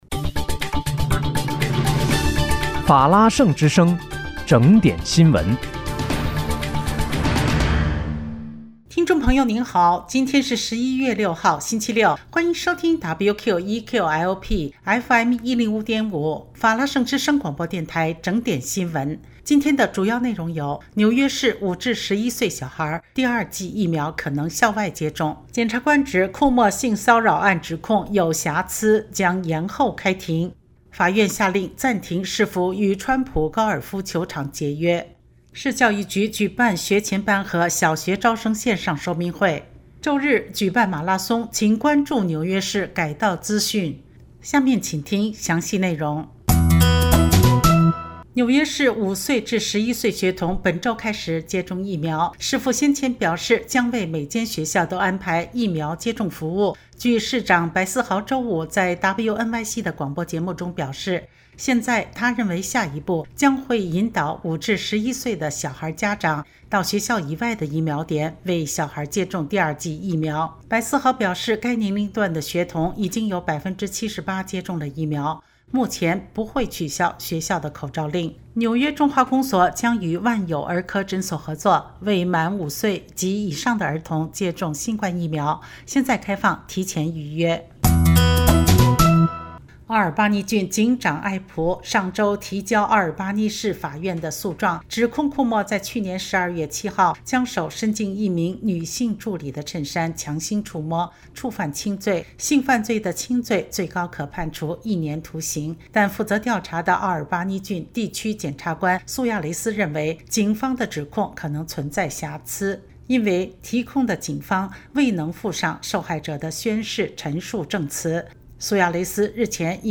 11月6日（星期六）纽约整点新闻